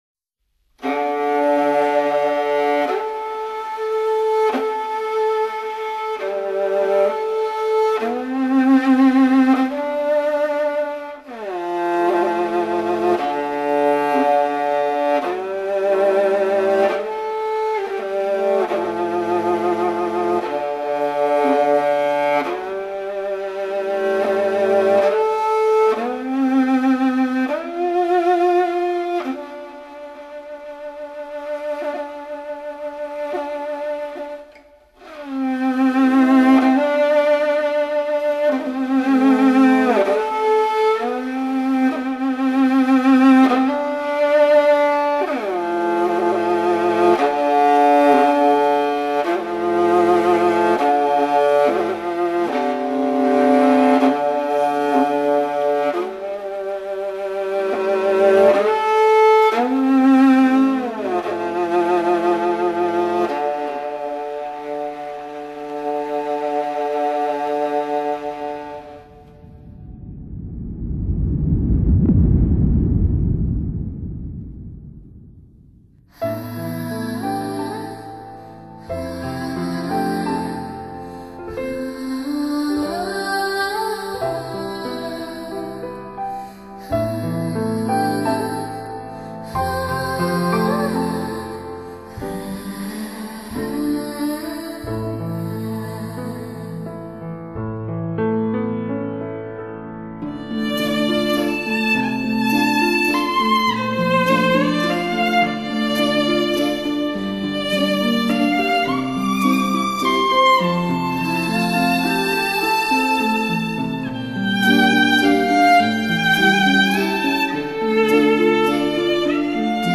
品原汁原味蒙古音乐 听千年流传草原歌声
深入草原腹地 攫取从未得到过的原生态极品天籁